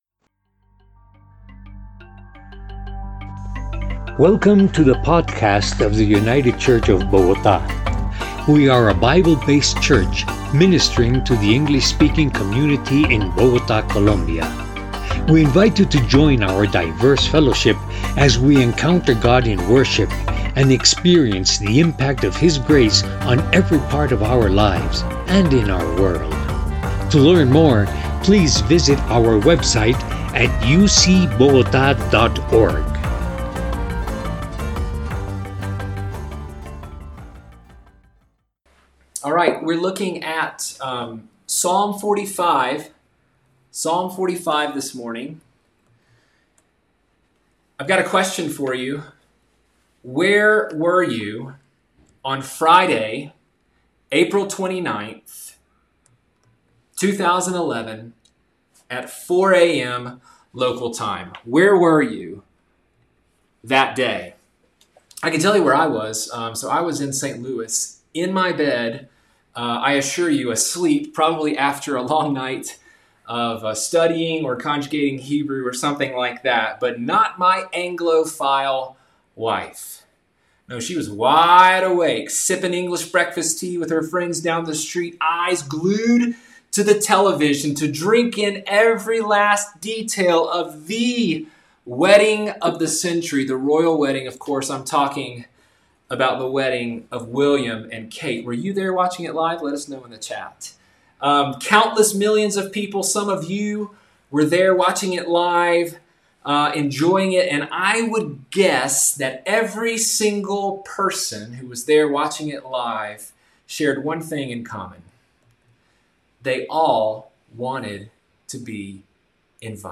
By United Church of Bogotá | 2020-07-19T16:30:59-05:00 July 5th, 2020 | Categories: Sermons | Tags: Summer Psalms | Comments Off on Are You Invited to the Royal Wedding?